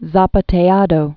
(zäpə-tādō, säpä-tĕ-)